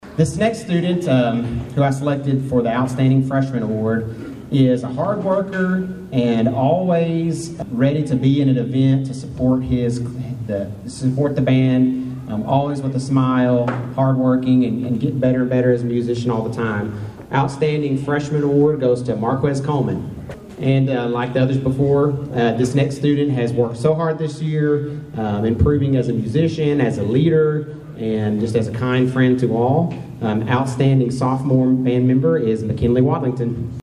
The Caldwell County High School Band of Pride held the end of the Band Banquet Friday night beginning in the high school cafeteria.